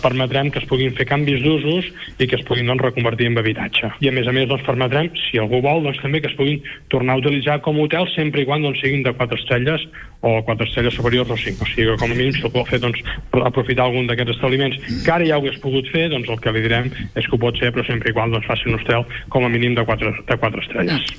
El govern actual ha anunciat una futura modificació urbanística que acabaria amb el blindatge d’usos que es va decretar amb un pla especial que pretenia conservar l’activitat turística en ple boom immobiliari. L’alcalde de Calella, Marc Buch (Junts), n’ha donat detalls al programa LA CIUTAT: